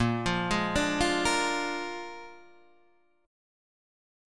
A#7 chord